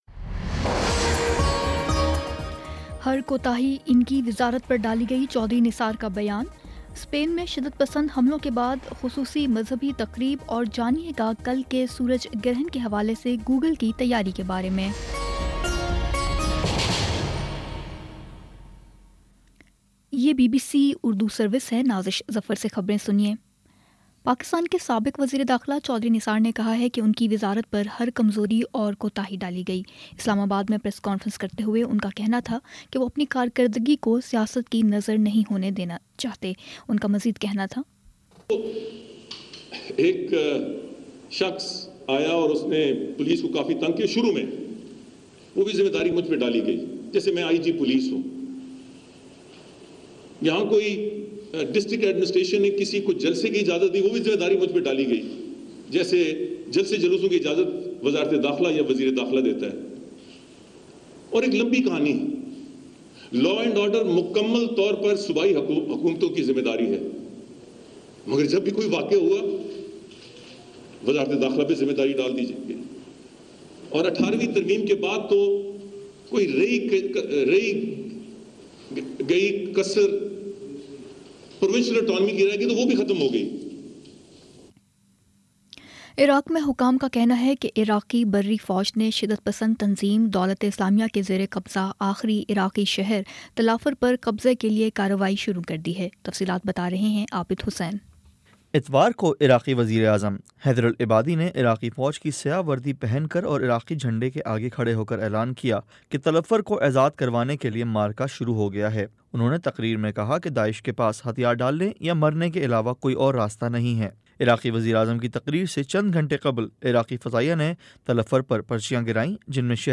اگست20 : شام سات بجے کا نیوز بُلیٹن